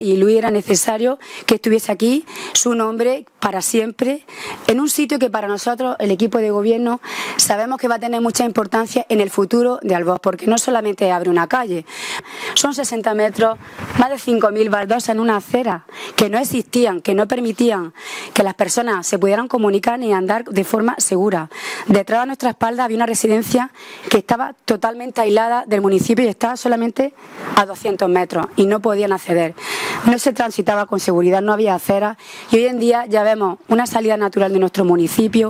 El vicepresidente, Fernando Giménez, y la alcaldesa, María del Mar Alfonso, inauguran la nueva calle Luis Pérez Granados que conectará el margen derecho del Río Almanzora con el centro del municipio
23-07_albox_nuevo_vial_alcaldesa.mp3